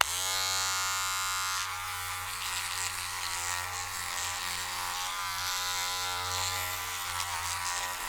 SHAVER 2  -S.WAV